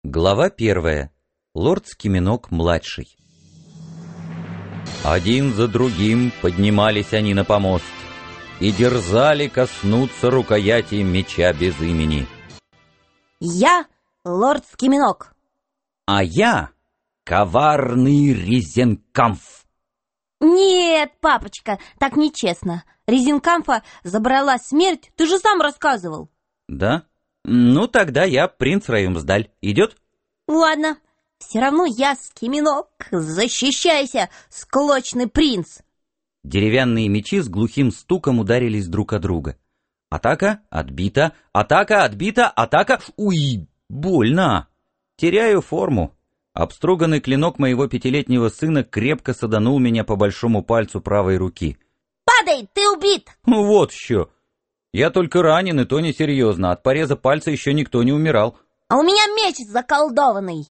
Аудиокнига Век святого Скиминока | Библиотека аудиокниг